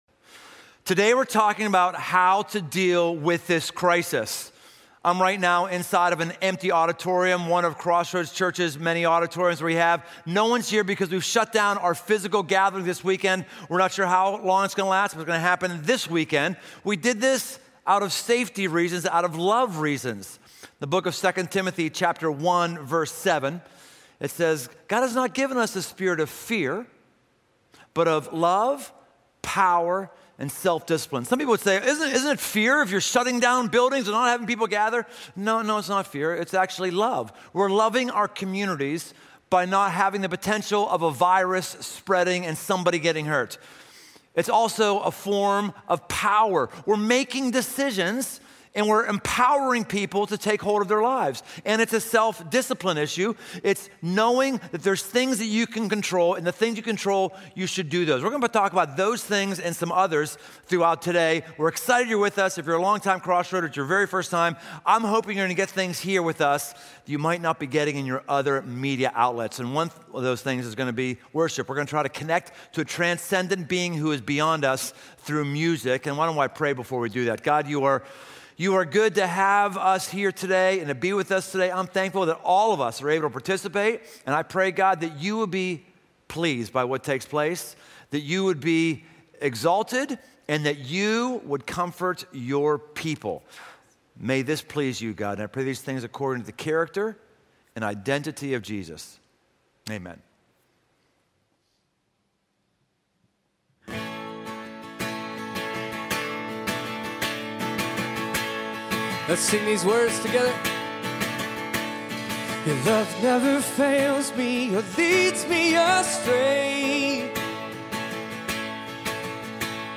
Genre Sermon